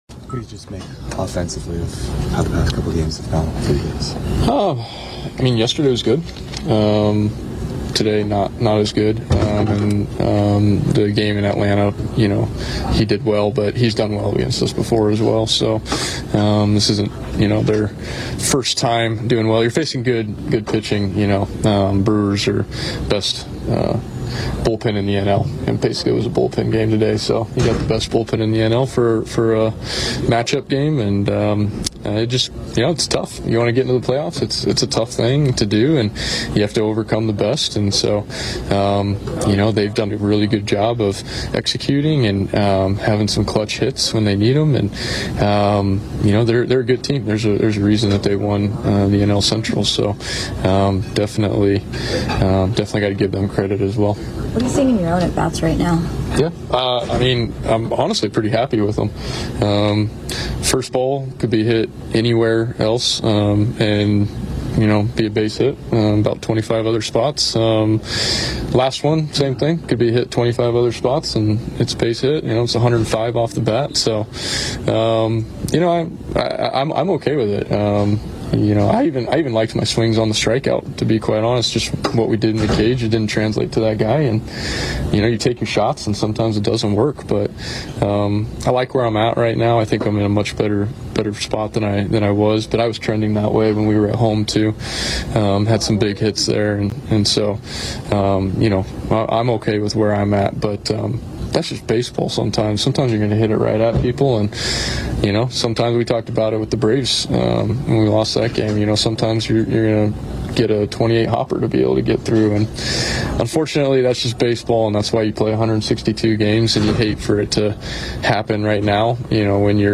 Mets outfielder Brandon Nimmo talks to the media after Saturday's loss to the Brewers.